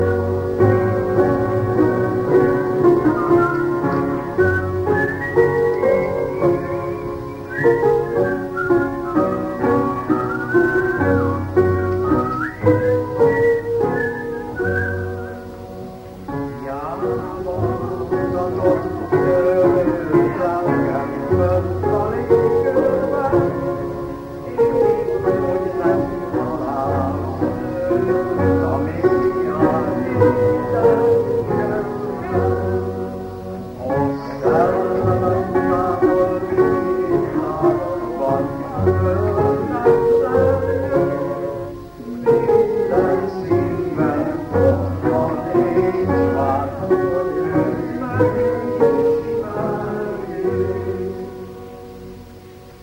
Magyar énekléssel (1990-es felvétel):